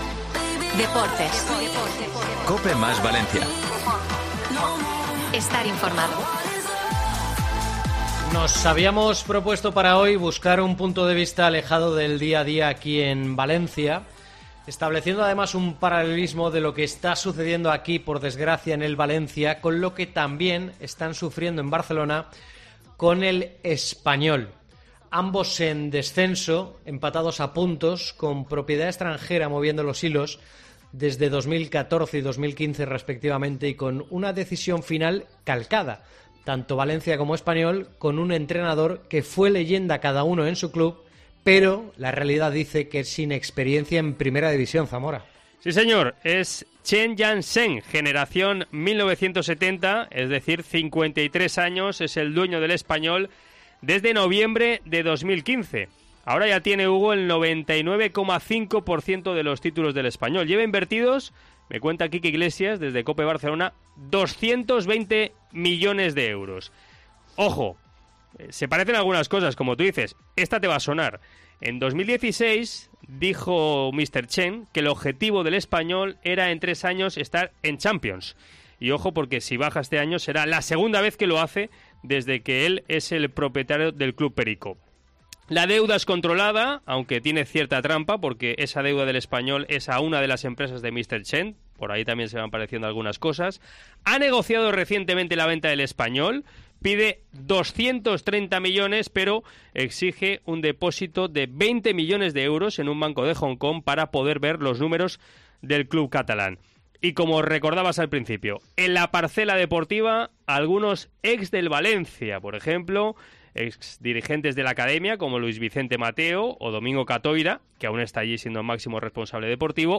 Tomás Guasch ha pasado hoy por los micrófonos de Deportes COPE Valencia para analizar la realidad de la zona baja de la tabla y los paralelismos entre el Valencia CF y el RCD Espanyol.